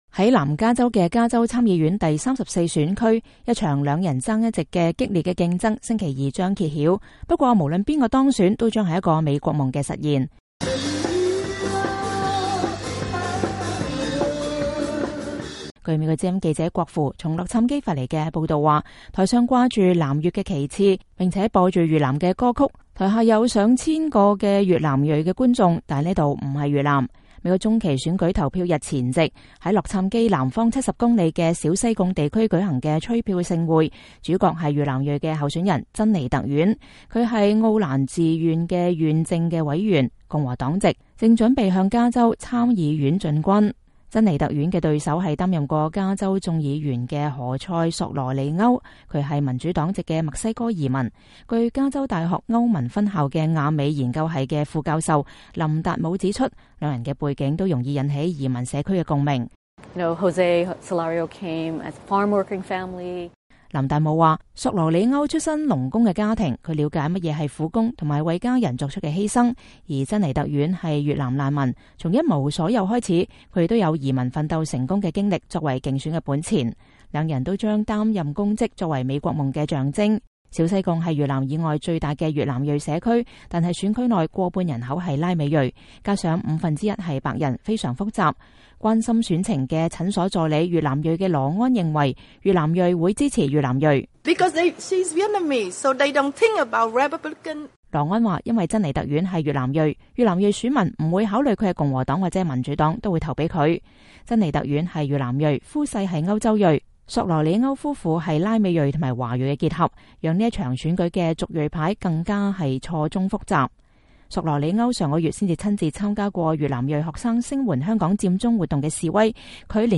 台上掛著南越旗幟，越南歌聲震人耳膜，台下有上千個越南裔觀眾，但，這裡不是越南。